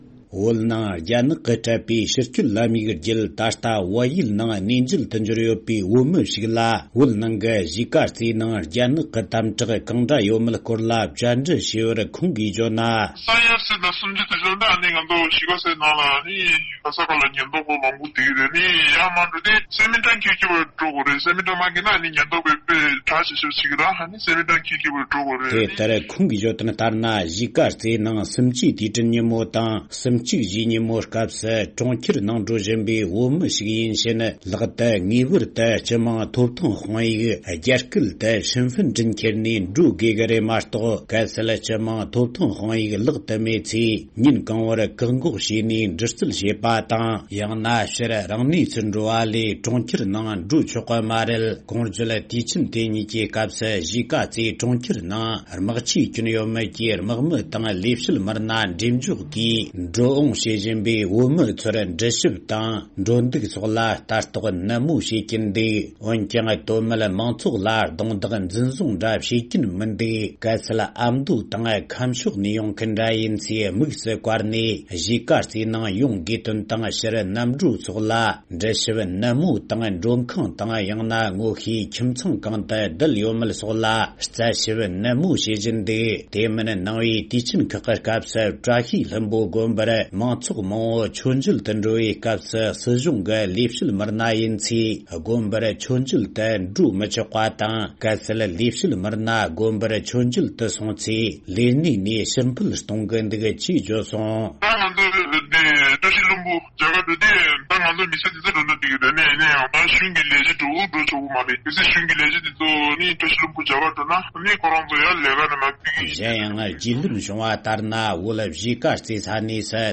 སྒྲ་ལྡན་གསར་འགྱུར། སྒྲ་ཕབ་ལེན།
༄༅། །གཞིས་ཀ་རྩེ་ནས་བལ་ཡུལ་ནང་གནས་མཇལ་དུ་ཡོང་མཁན་ཞིག་ལ་བཅར་འདྲི་ཞུས་བའི་སྐོར།